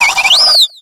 Cri de Papilord dans Pokémon X et Y.